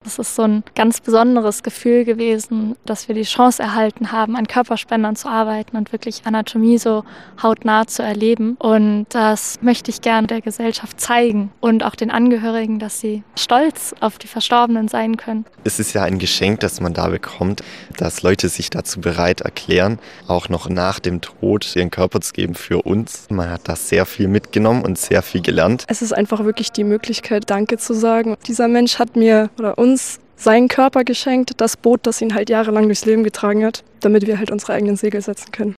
Anatomie-Gottesdienst im Ulmer Münster
Mit einem feierlichen Gottesdienst im Ulmer Münster nehmen Angehörige, Freunde und Studierende Abschied von Menschen, die ihren Körper der Wissenschaft gespendet haben.